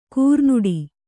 ♪ kūrnuḍi